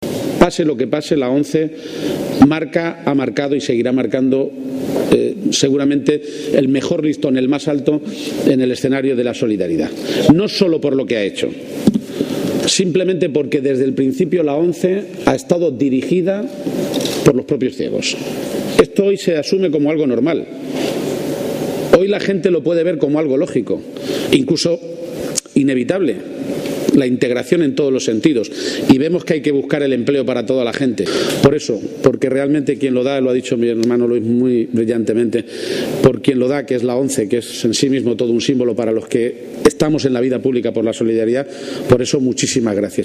En Toledo, con el presidente García-Page